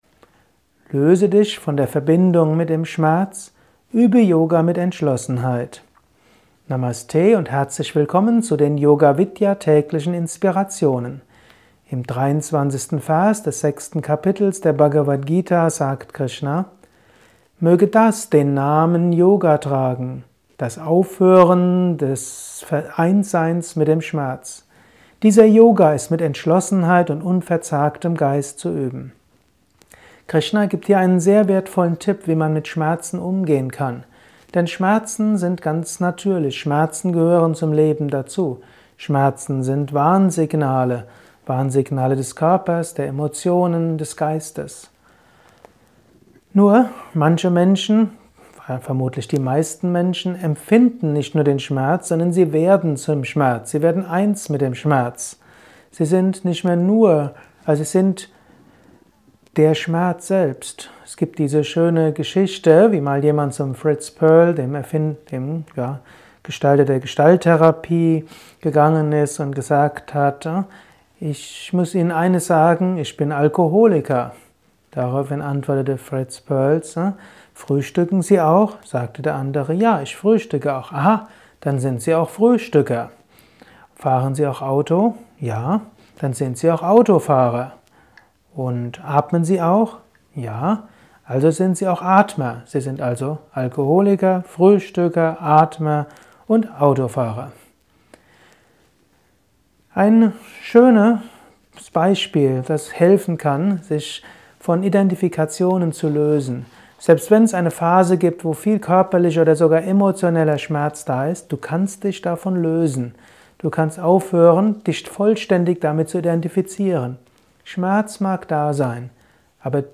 Kurzvorträge